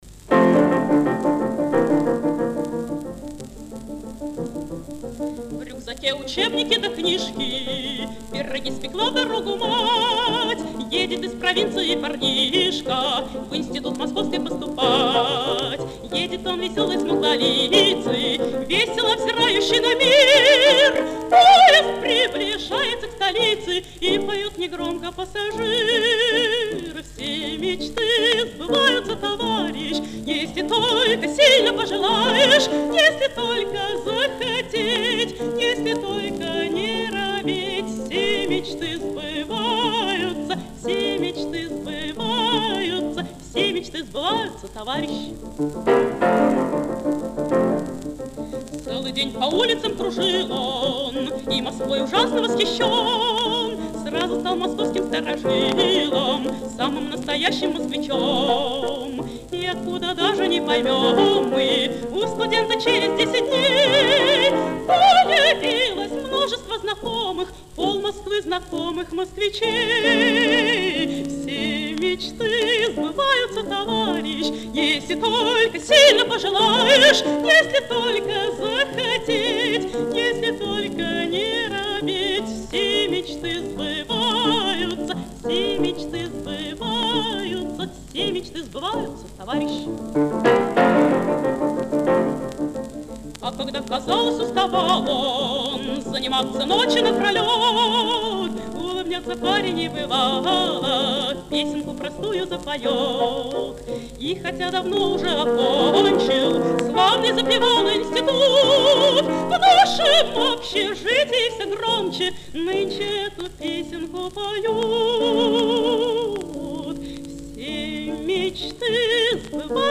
Радостная песня о жизни советской молодёжи.
ф-но